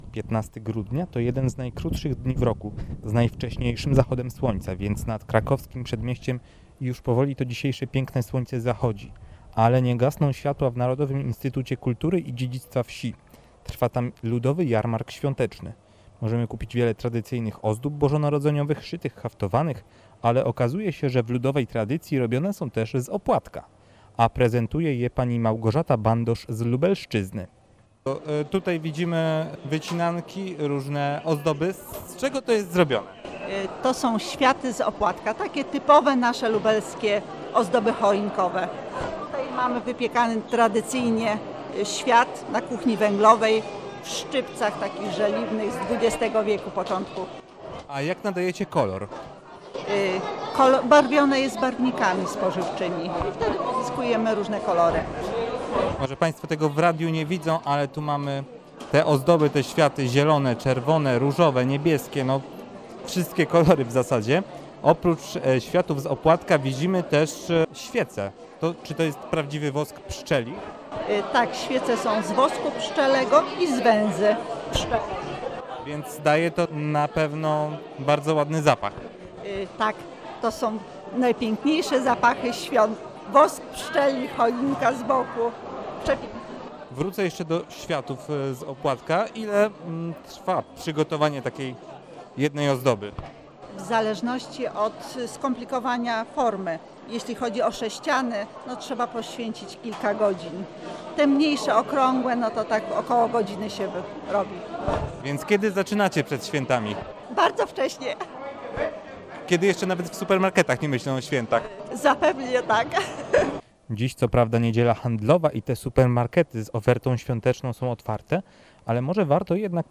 Muzyka, ozdoby świąteczne oraz regionalne potrawy – to główne atrakcje Jarmarku Świątecznego, który odbył się w Narodowym Instytucie Kultury i Dziedzictwa Wsi na Krakowskim Przedmieściu w Warszawie.